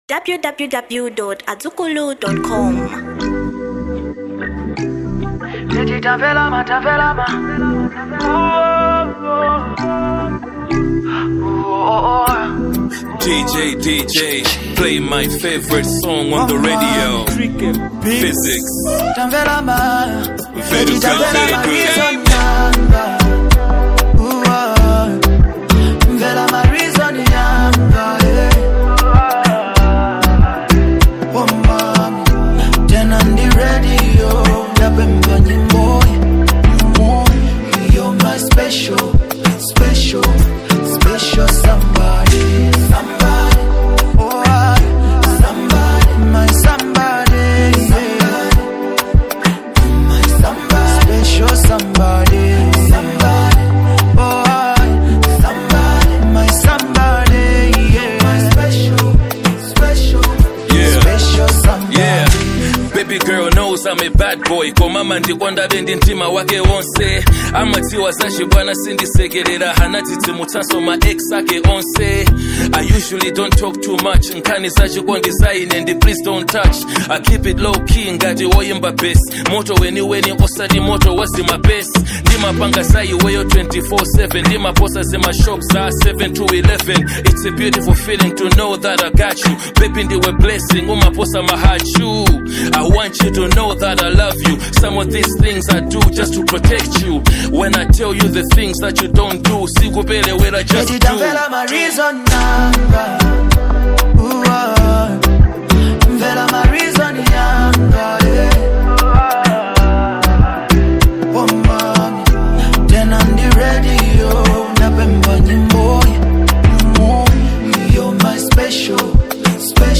Genre Afrobeats